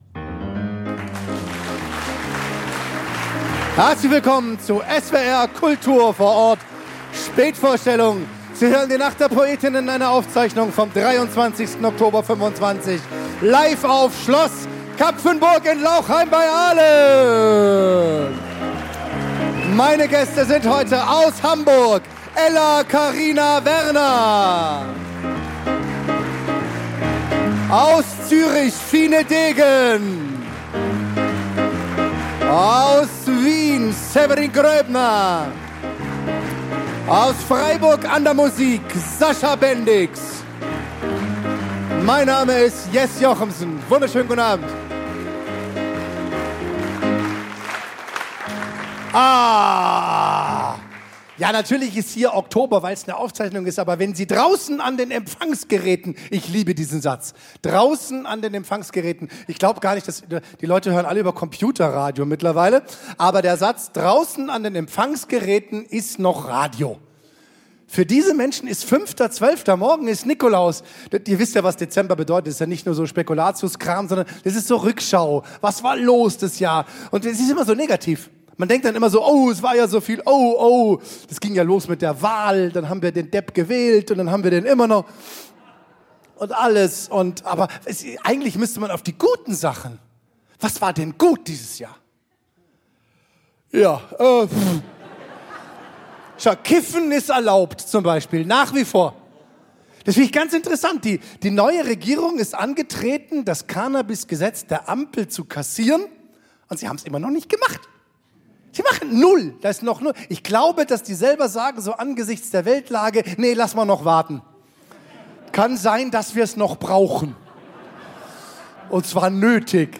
Ein Abend der komischen Literatur